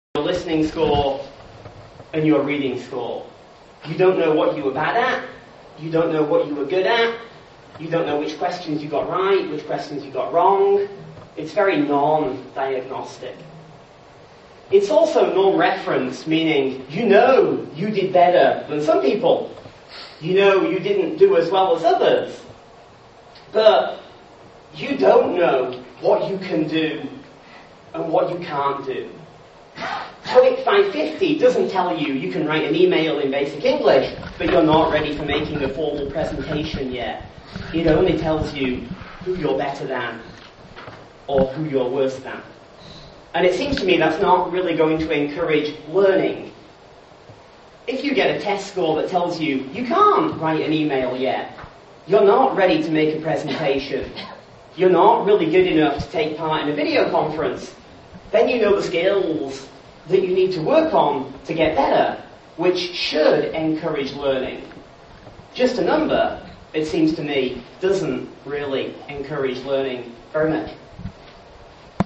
Photos & Sound Clips from the JALT 2005 Pan-SIG Conference
TEVAL SIG Panel Clip